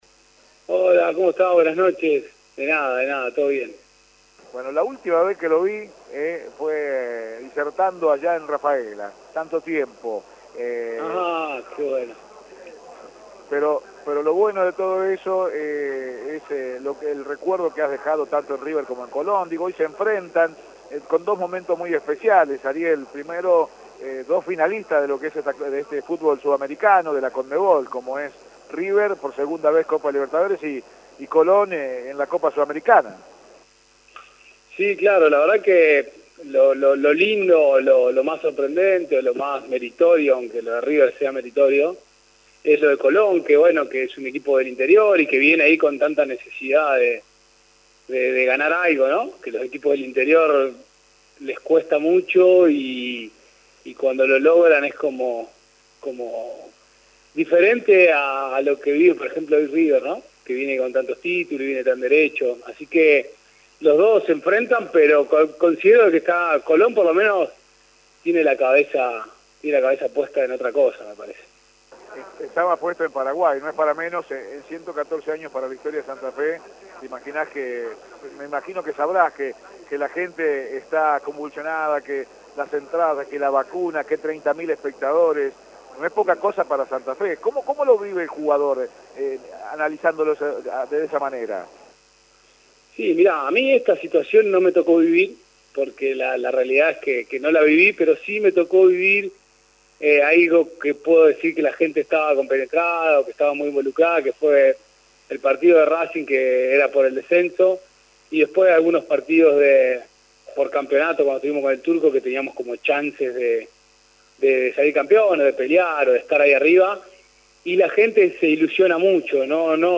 En la previa del encuentro entre River y Colón dialogamos con Ariel Garcé, jugador que pasó por ambos clubes.